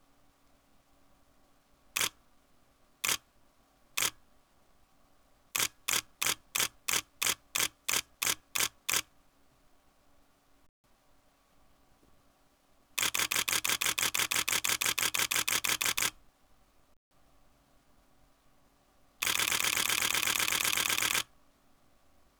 The third source I created recording a camera shutter going off at 8 fps (back in 2007).